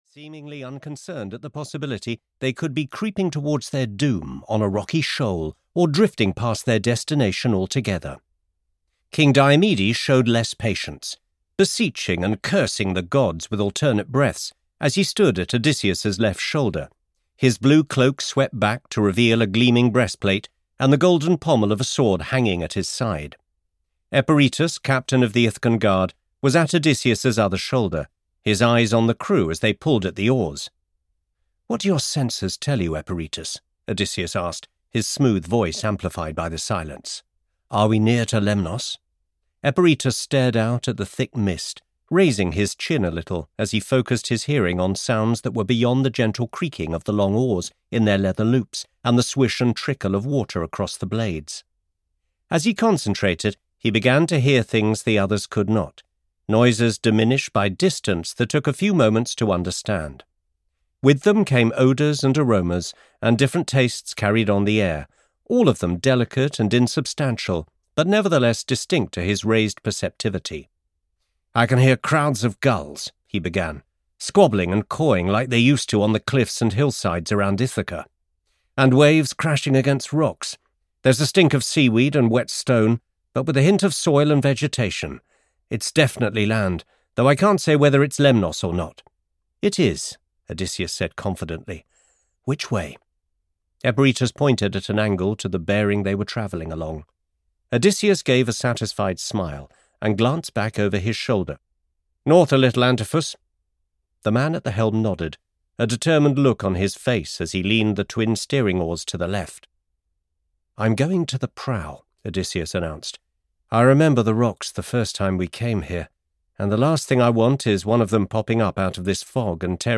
The Oracles of Troy (EN) audiokniha
Ukázka z knihy